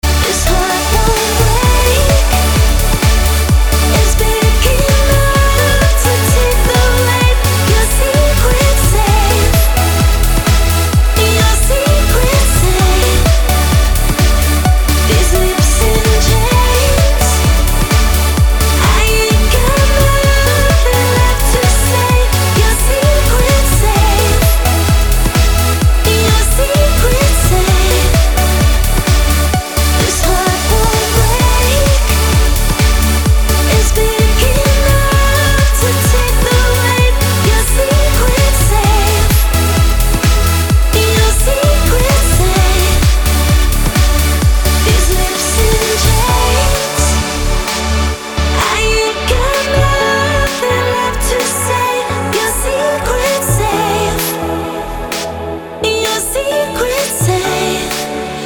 красивые
женский вокал
dance
vocal